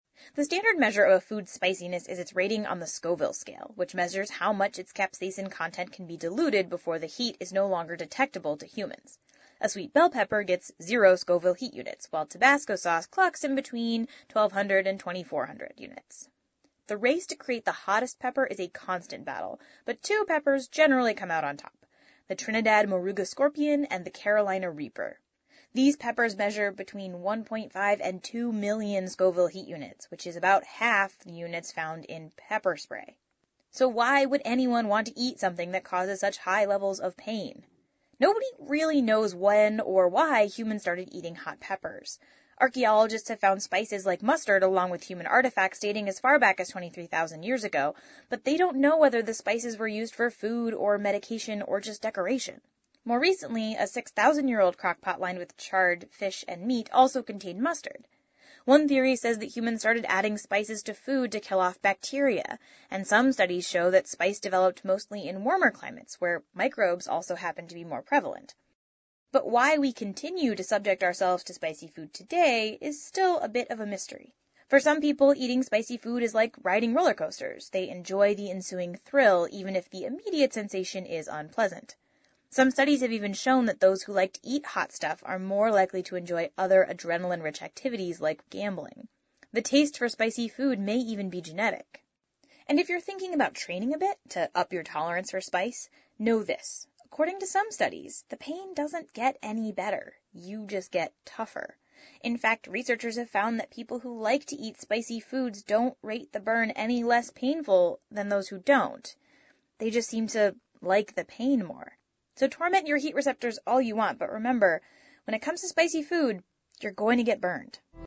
TED演讲:辣味的科学(2) 听力文件下载—在线英语听力室